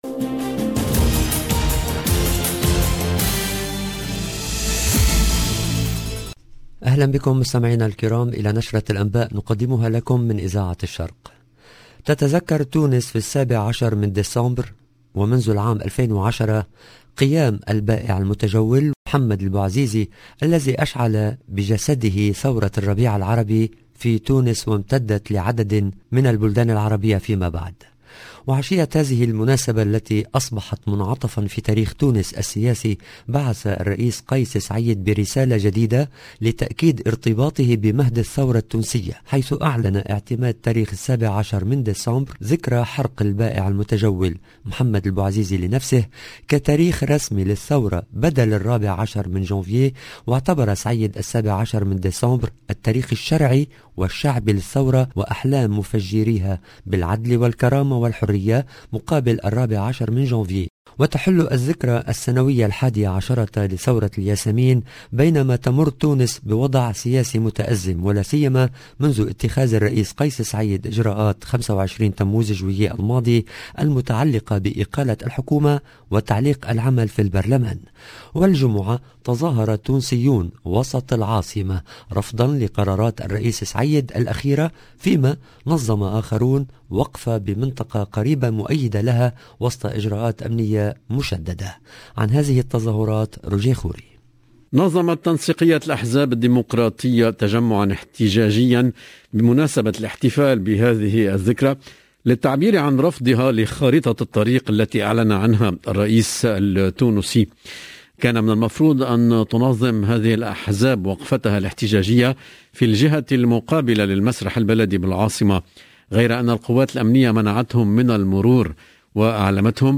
EDITIPN DU JOURNAL DU SOIR EN LANGUE ARABE